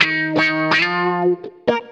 WAV guitarlicks